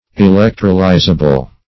Meaning of electrolyzable. electrolyzable synonyms, pronunciation, spelling and more from Free Dictionary.
Search Result for " electrolyzable" : The Collaborative International Dictionary of English v.0.48: Electrolyzable \E*lec"tro*ly`za*ble\, a. Capable of being electrolyzed, or decomposed by electricity.